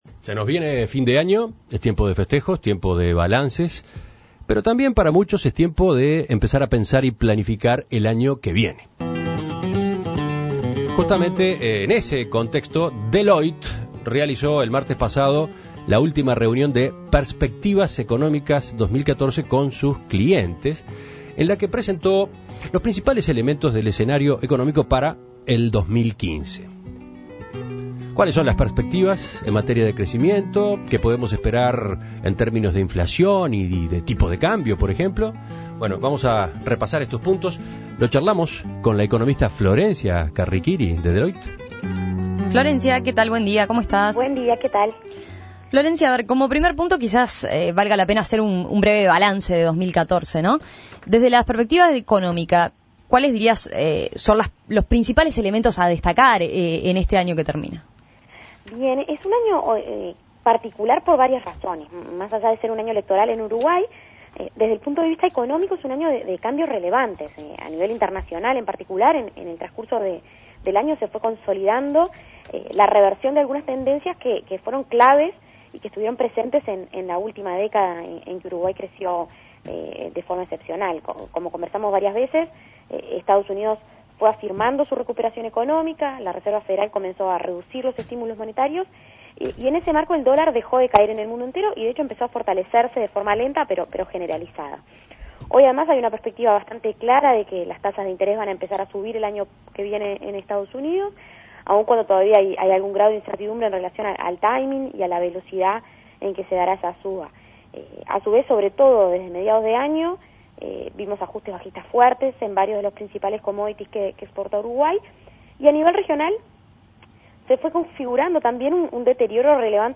(emitido a las 7.53 hs.)